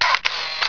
Here are some ripped soundFX from TR2 using Cool Edit Pro.